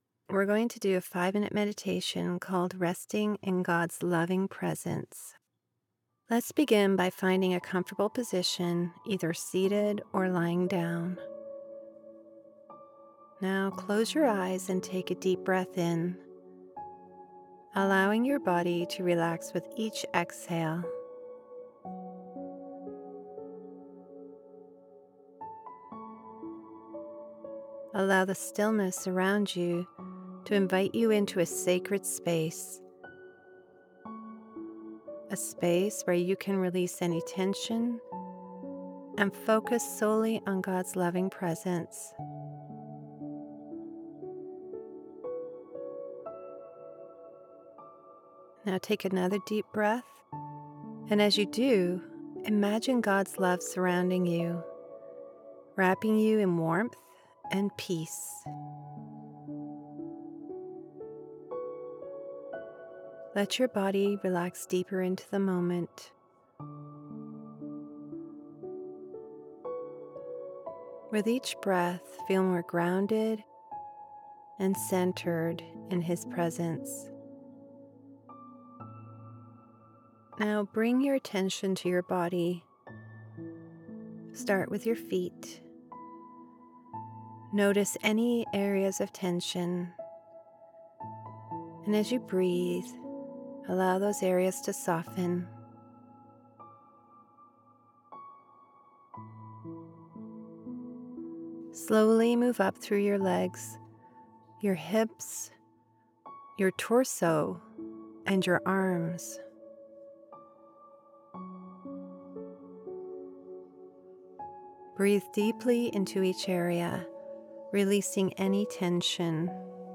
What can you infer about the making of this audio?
Neumann TLM 103/ Sennheiser MKH 416 Professionally Treated Space